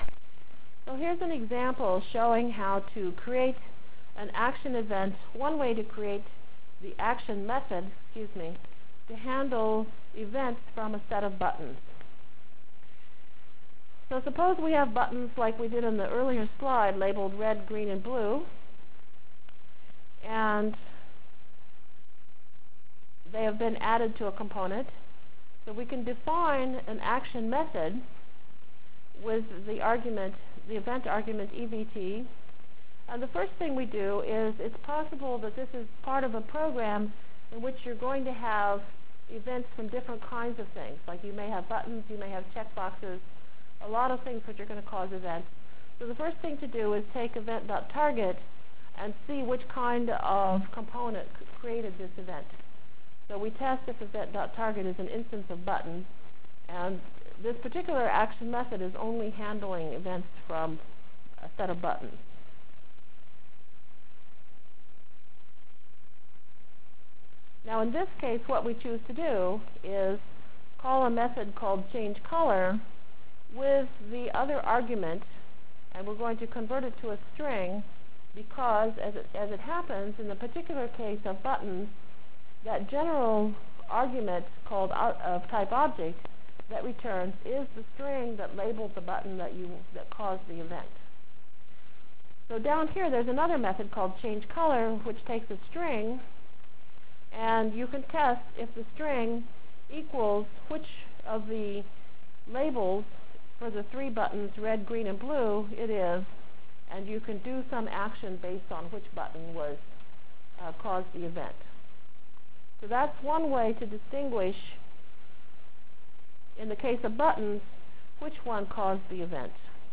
From Feb 3 Delivered Lecture for Course CPS616 -- Java Lecture 4 -- AWT Through I/O CPS616 spring 1997 -- Feb 3 1997.